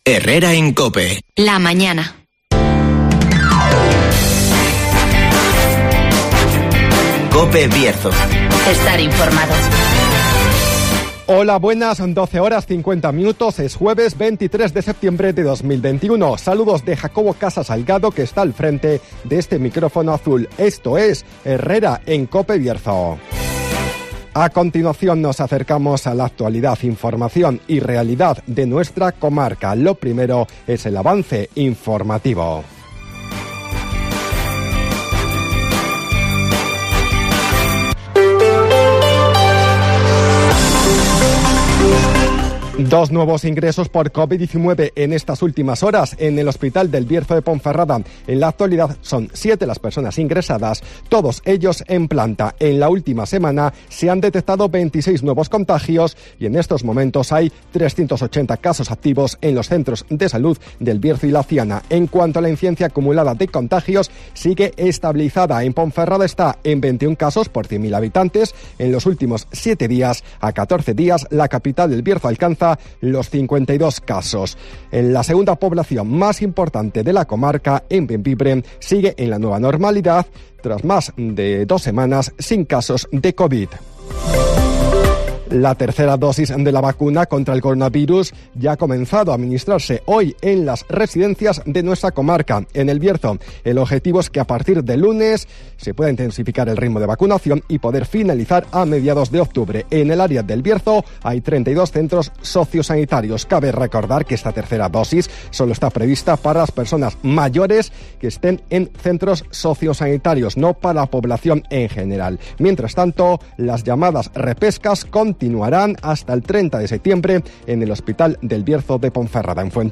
Avance informativo, El Tiempo y Agenda